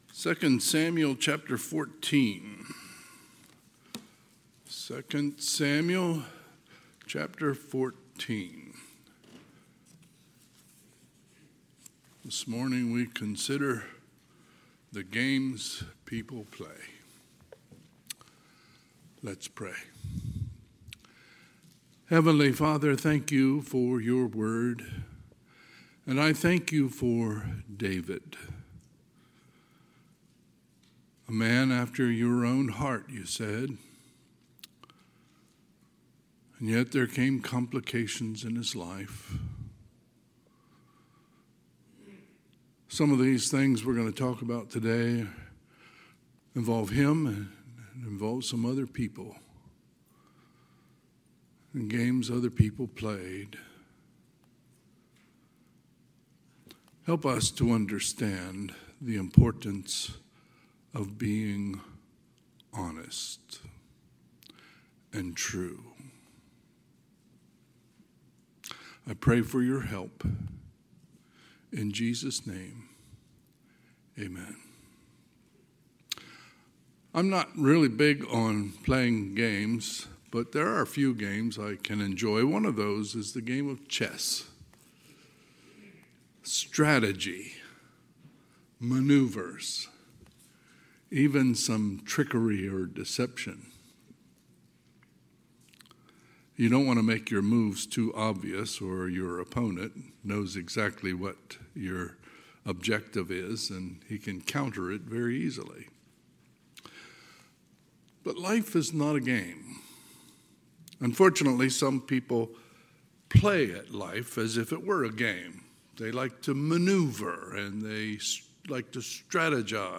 Sunday, September 7, 2025 – Sunday AM